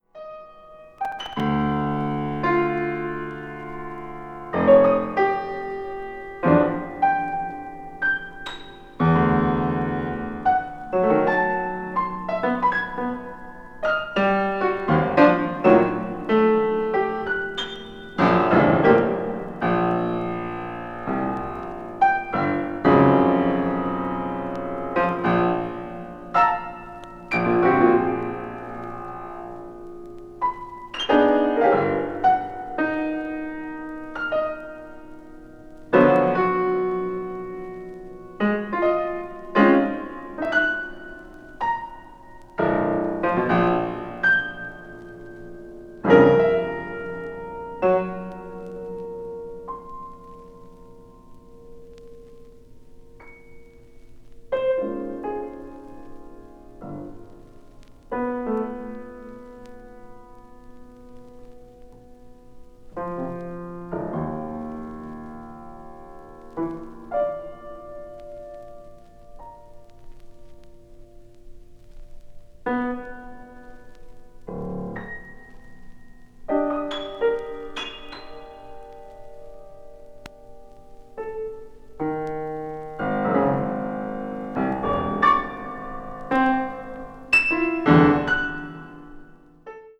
media : EX-/EX-,EX-/EX-(ごく薄いスリキズによるわずかなチリノイズが入る箇所あり)